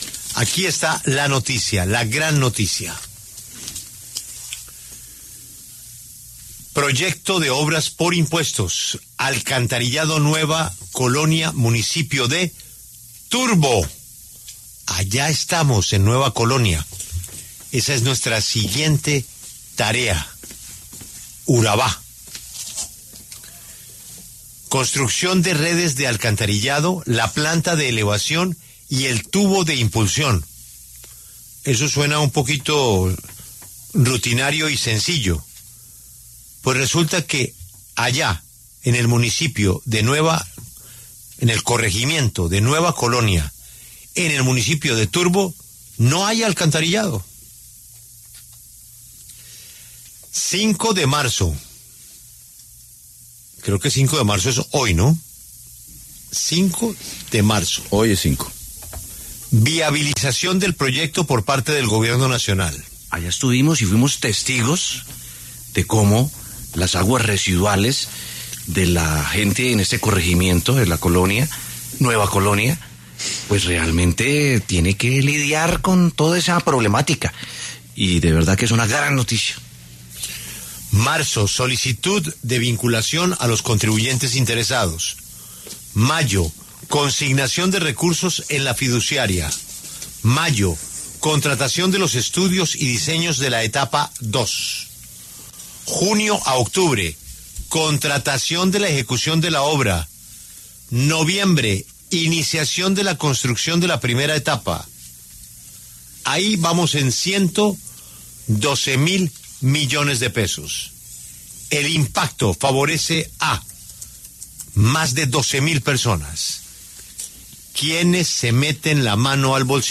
Julio Sánchez Cristo, director de La W, compartió detalles sobre la iniciativa Juntos Por Urabá que este 5 de marzo emprenderá la búsqueda de un nuevo hito: hacer realidad el alcantarillado en el corregimiento Nueva Colonia, del municipio de Turbo.